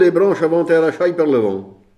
Mémoires et Patrimoines vivants - RaddO est une base de données d'archives iconographiques et sonores.
Localisation Saint-Hilaire-des-Loges
Catégorie Locution